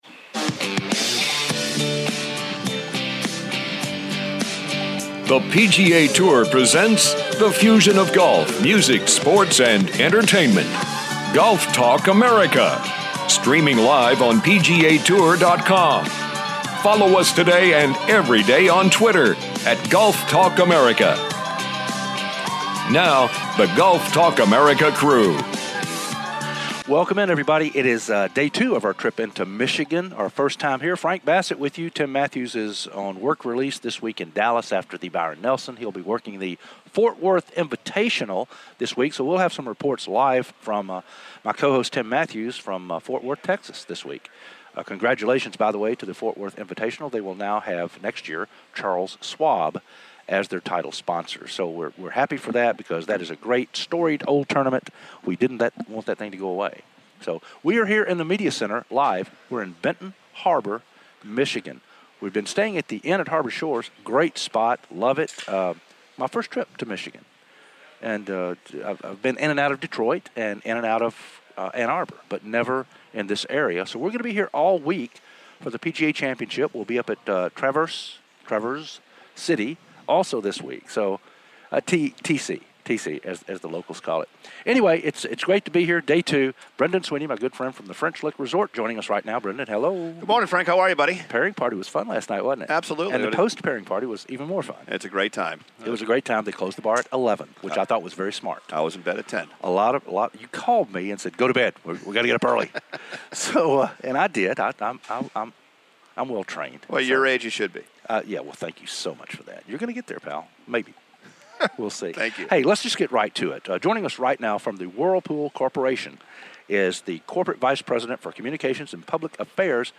Rocco Mediate Joins Us "LIVE" From The Kitchen Aid Senior PGA Championship Plus some special Guests "PURE MICHIGAN BABY!"